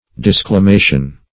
Disclamation \Dis`cla*ma"tion\, n. A disavowing or disowning.
disclamation.mp3